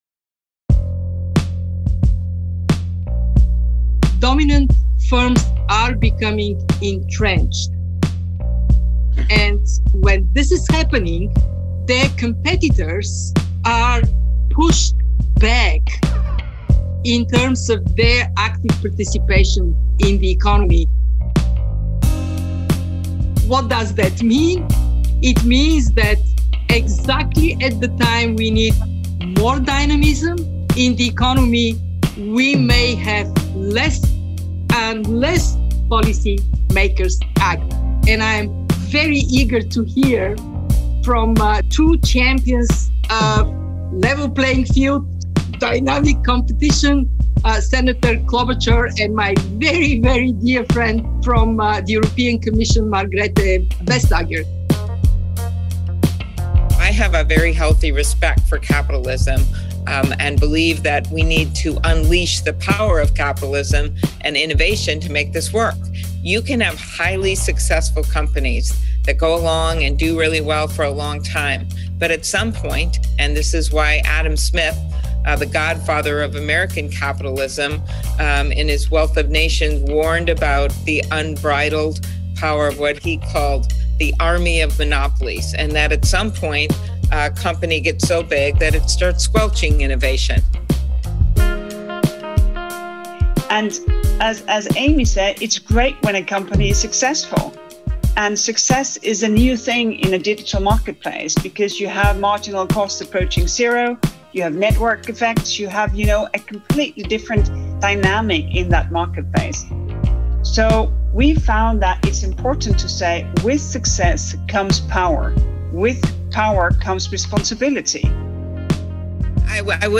The Brookings Institution and Bruegel hosted a conversation with Kristalina Georgieva, IMF Managing Director, U.S. Senator Amy Klobuchar (D-Minn.), and Margrethe Vestager, Executive Vice President of the European Commission, to talk about the research and discuss policy responses.